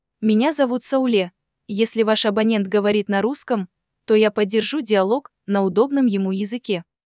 Синтез речи
И буквально 2 недели назад выпустили в продакшен голос по имени Сауле.
Сауле говорит на русском
Сауле может говорить добродушно и строго, на казахском и на русском, поэтому умеет быть разной и подстраиваться под сценарии клиента.